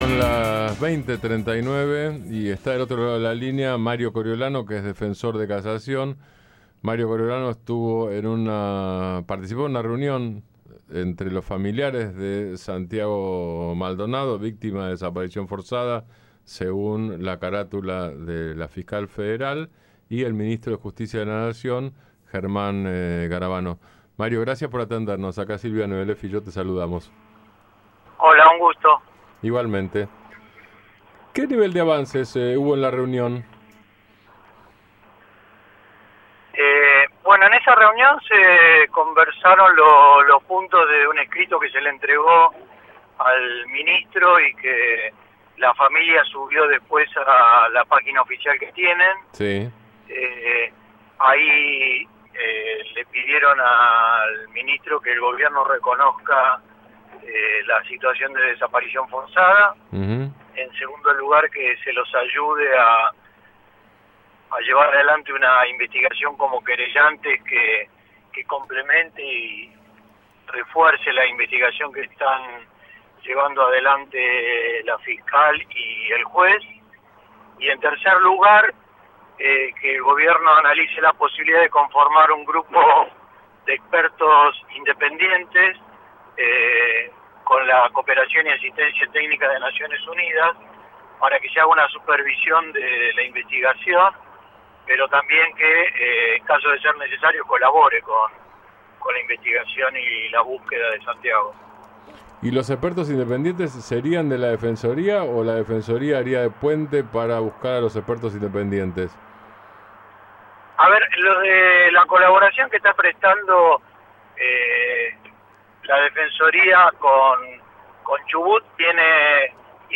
El dr. Mario Coriolano, defensor ante el Tribunal de Casación Penal de la provincia de Buenos Aires, se refirió en las últimas horas al caso de Santiago Maldonado y a su participación en la reunión que este martes convocó a la familia del joven artesano desaparecido y al ministro de Justicia, Germán Garavano representando al Gobierno Nacional.